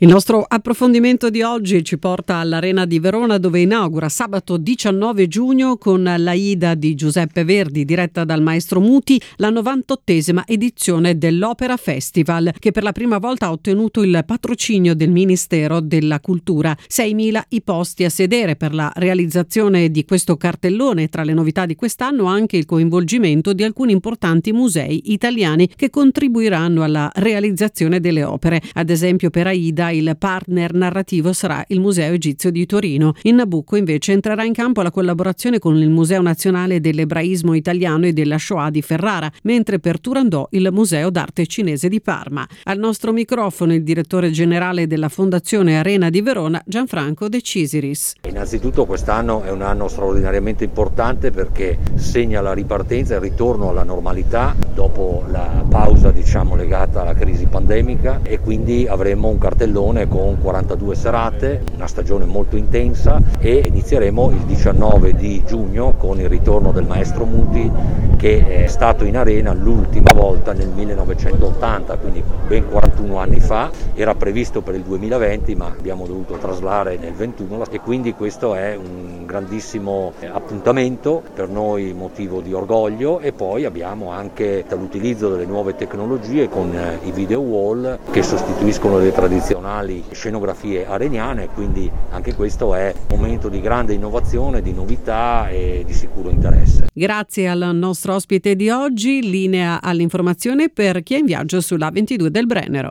16/06: sabato 19 giugno si inaugura la nuova stagione dedicata all’Opera all’Arena di Verona, l’edizione numero 98 che si apre con l’Aida di Verdi, diretta dal M° riccardo Muti. La nostra intervista